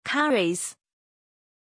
Pronunciation of Charis
pronunciation-charis-zh.mp3